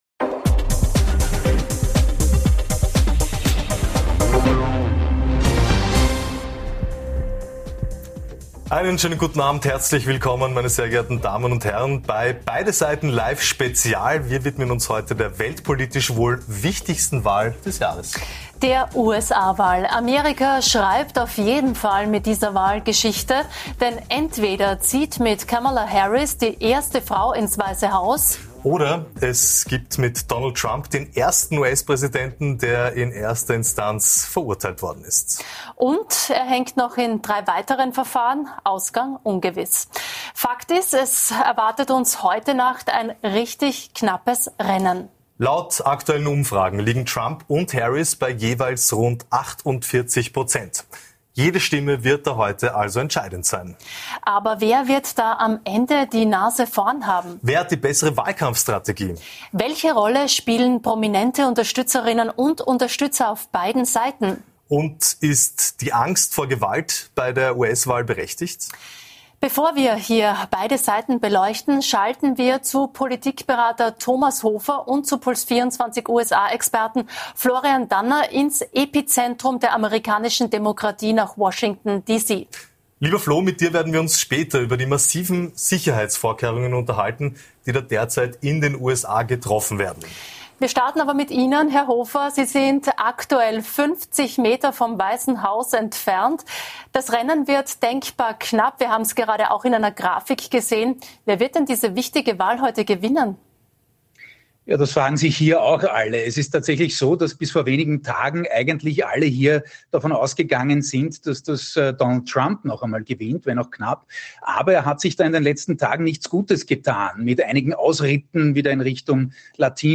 US-Wahl: Wie groß ist die Angst vor der Nach-Wahl-Eskalation? Und nachgefragt haben wir heute bei gleich zwei Gästen - Im großen Beide Seiten Live Duell